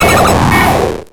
Cri de Qulbutoké dans Pokémon X et Y.